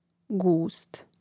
gȗst gust